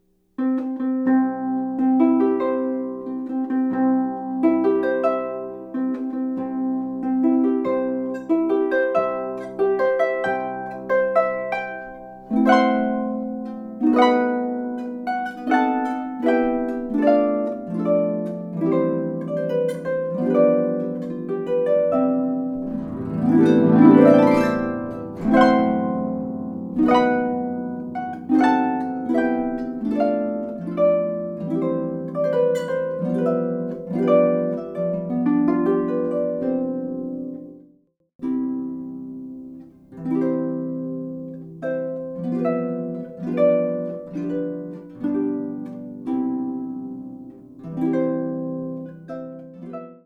Harpist